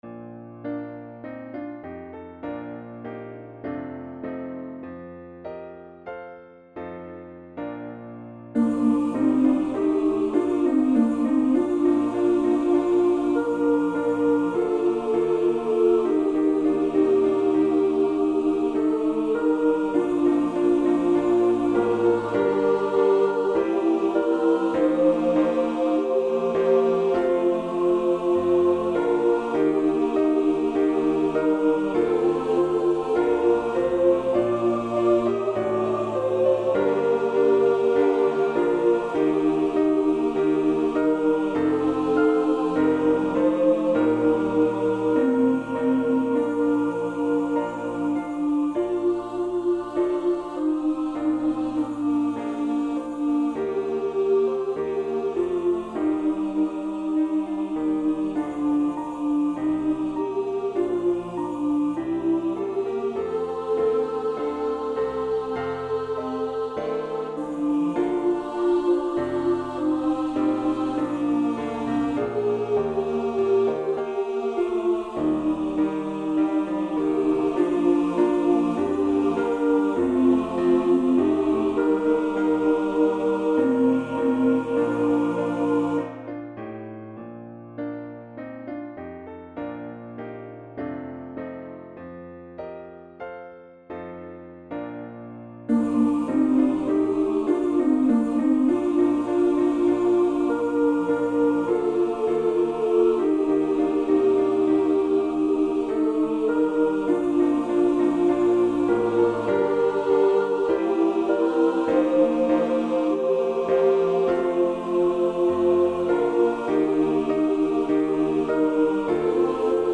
(S)SATB and Piano
Choral
Anthem
Church Choir